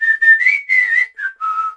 darryl_lead_vo_07.ogg